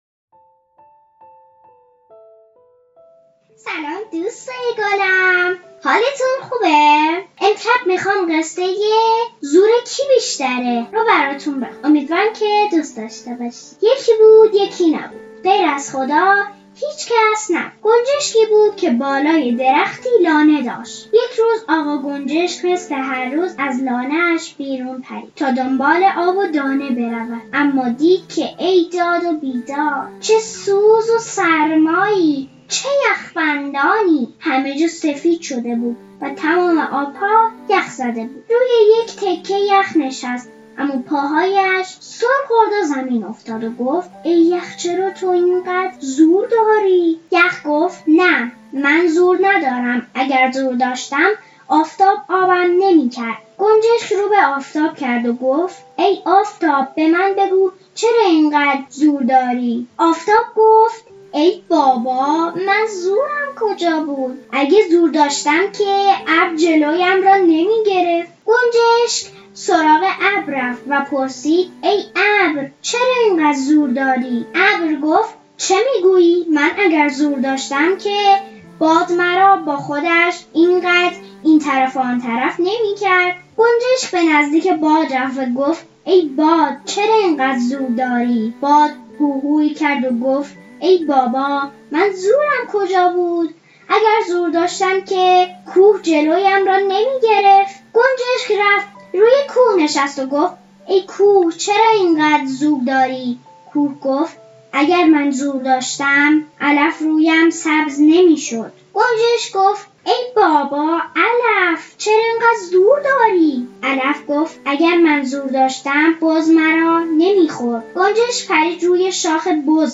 قصه کودکان
قصه صوتی